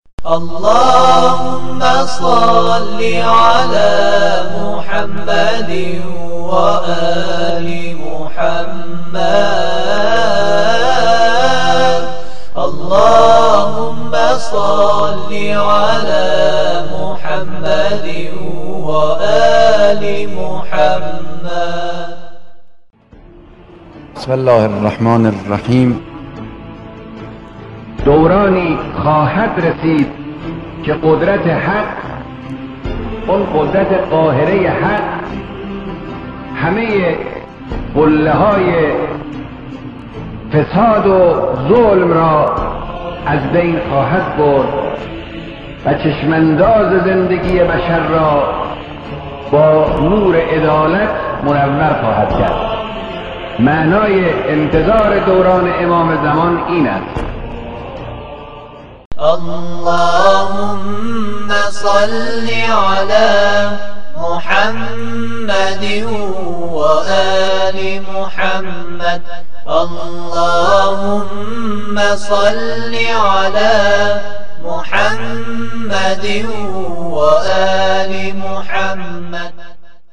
صوت بیانات رهبر معظم انقلاب